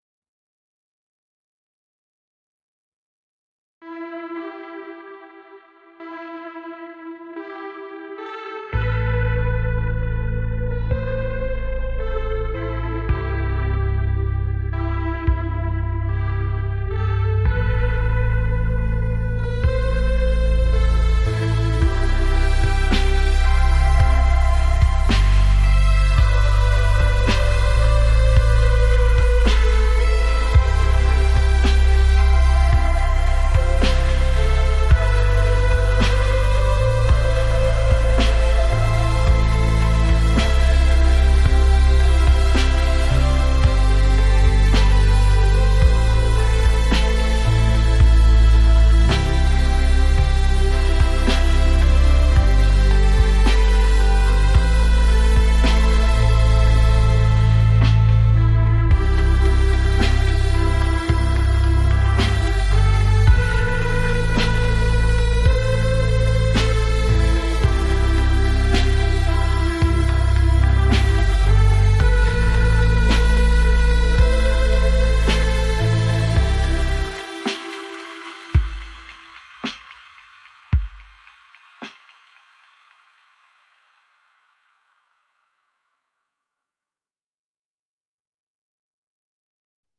小川がゆるやかに流れる音が、鳥のさえずりや風のささやきと調和し、森全体に穏やかなリズムを与えている。